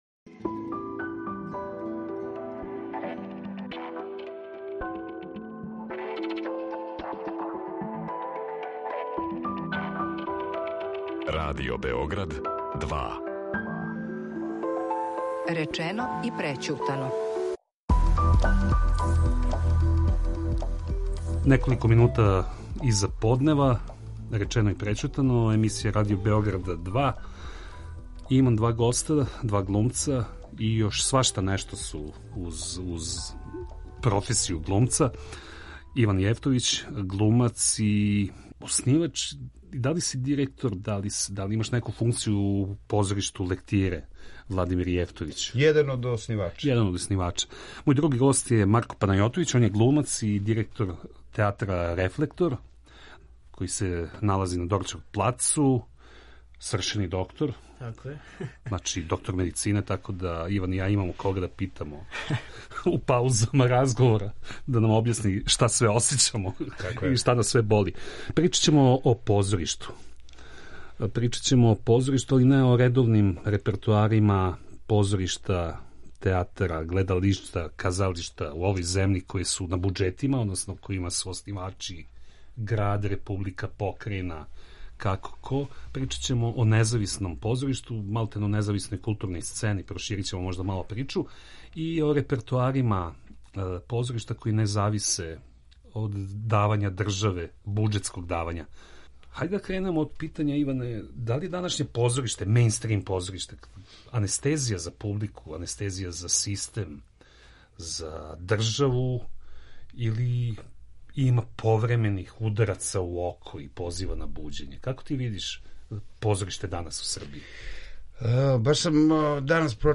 Гости Радио Београда 2 су глумац